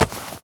foley_object_grab_pickup_03.wav